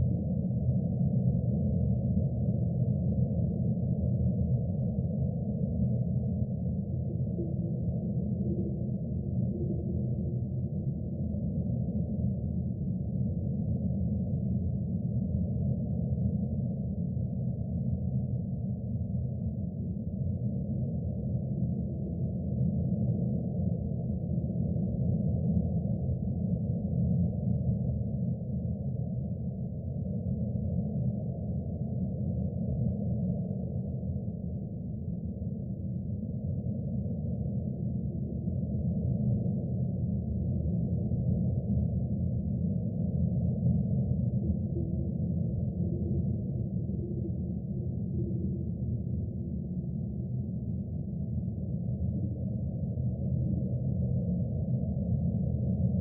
EerieAmbience.wav